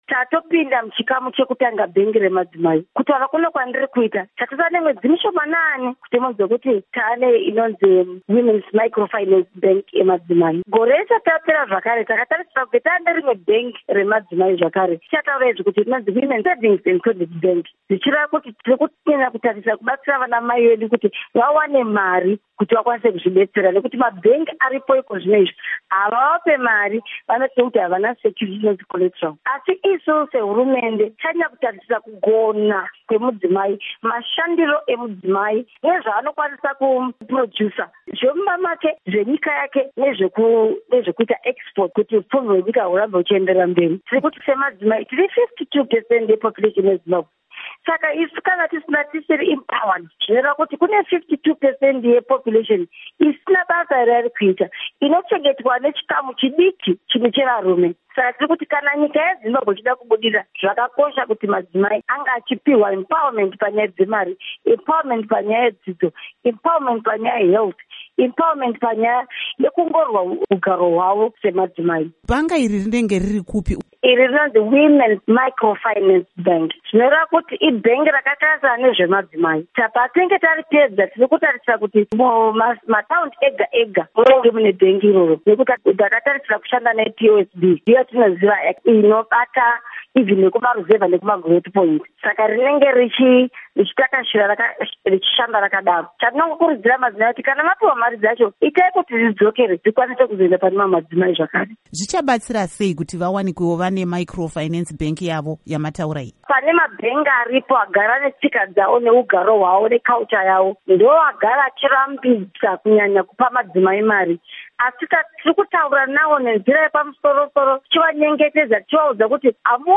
Hurukuro NaAmai Nyasha Chikwinya